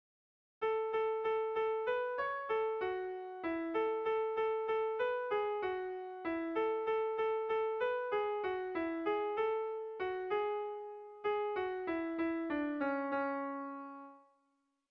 Dantzakoa
AB